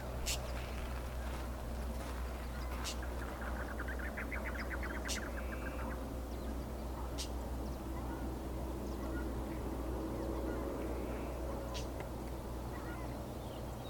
White-banded Mockingbird (Mimus triurus)
Contacto (Hay tambien unos chotoy que molestan en el audio)
Condition: Wild
Certainty: Observed, Recorded vocal
CALANDRIA-REAL.mp3